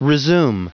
Prononciation du mot resume en anglais (fichier audio)
Prononciation du mot : resume